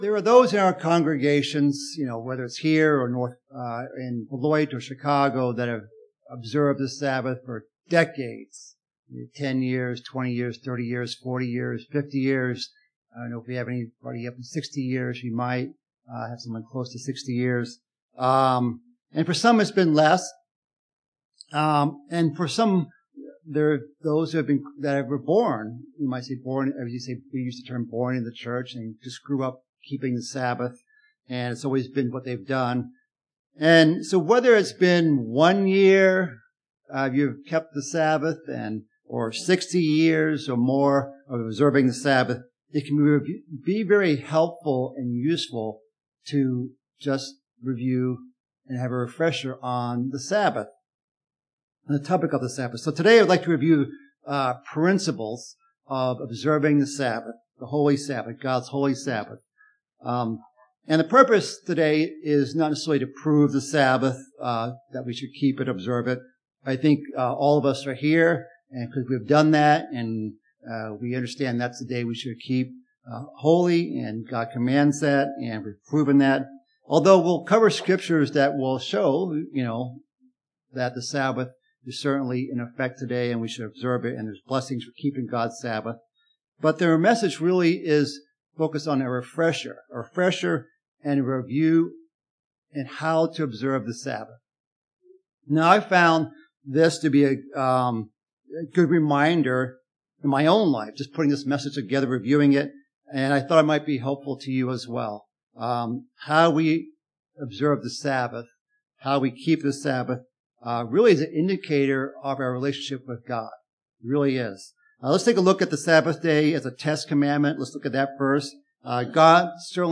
This is the Sabbath Note that went out to compliment the sermon message